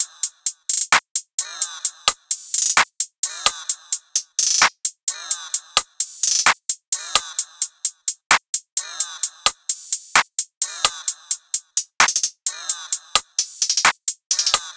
SOUTHSIDE_beat_loop_trill_top_02_130.wav